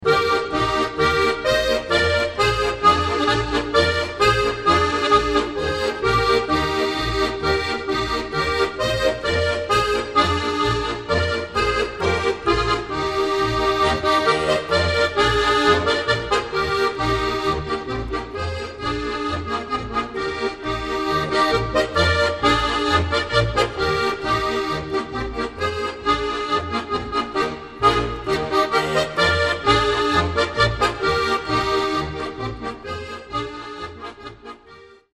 Volksmusik